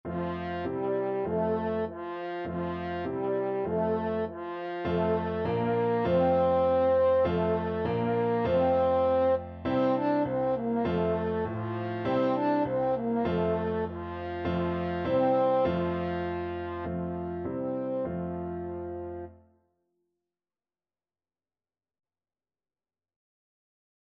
French Horn
F major (Sounding Pitch) C major (French Horn in F) (View more F major Music for French Horn )
4/4 (View more 4/4 Music)
Traditional (View more Traditional French Horn Music)